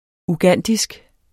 ugandisk adjektiv Bøjning -, -e Udtale [ uˈganˀdisg ] Betydninger fra Uganda; vedr.